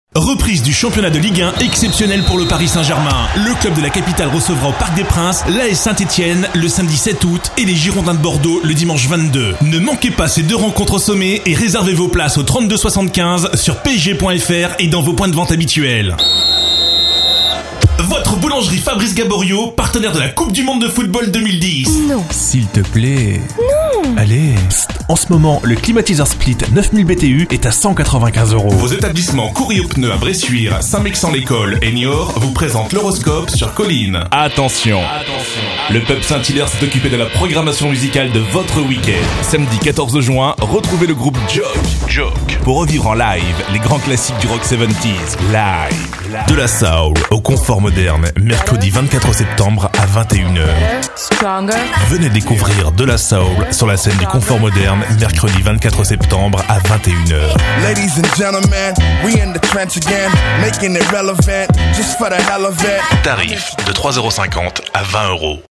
Voix masculine
Voix Graves